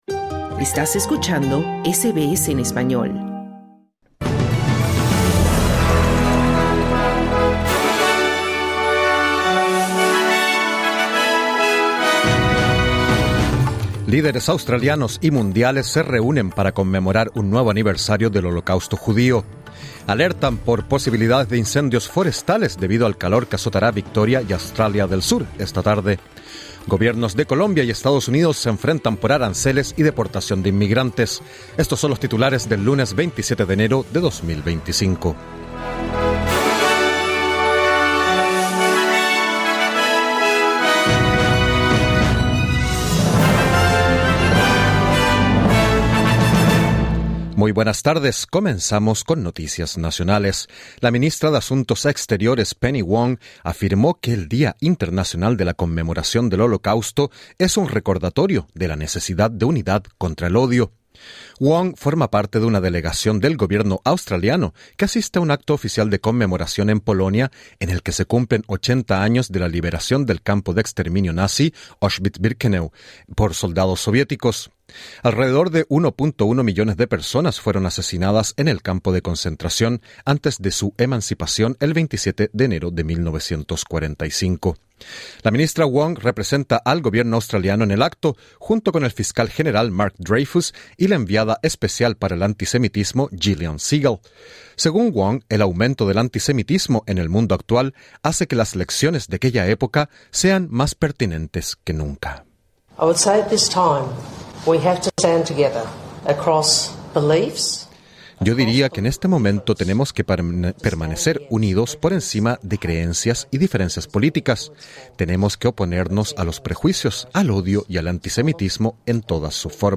Escucha el boletín en el podcast localizado en la parte superior de esta página.